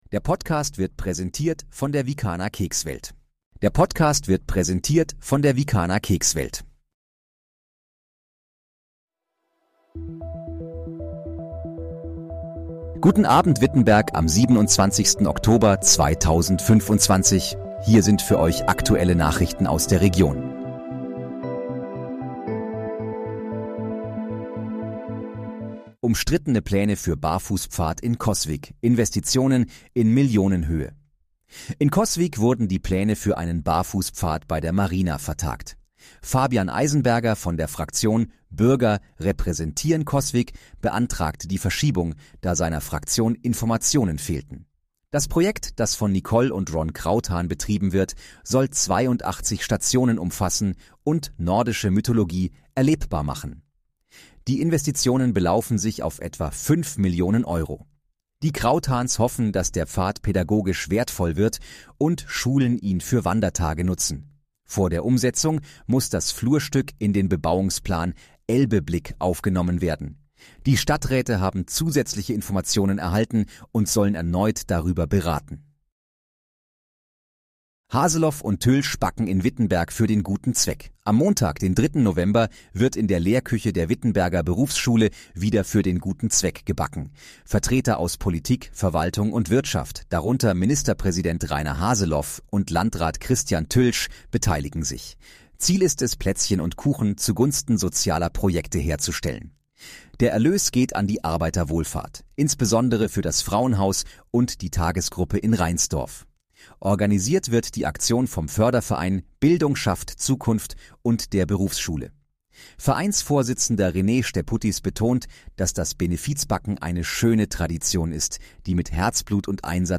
Guten Abend, Wittenberg: Aktuelle Nachrichten vom 27.10.2025, erstellt mit KI-Unterstützung
Nachrichten